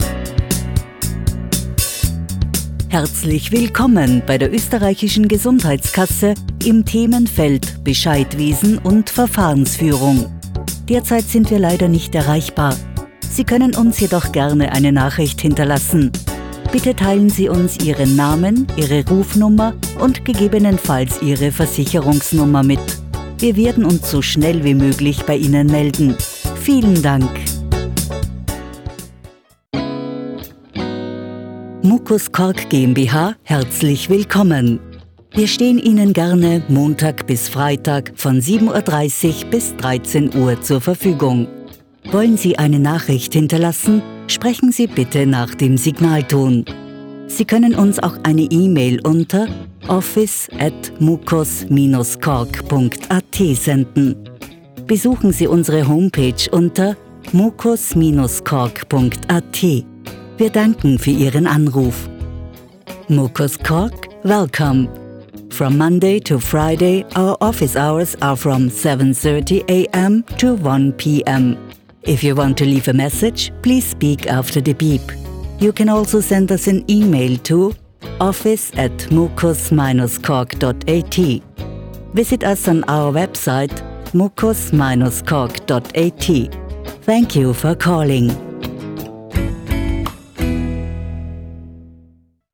Deutsch (Österreich)
Zuverlässig, Freundlich, Natürlich, Senior, Warm
Telefonie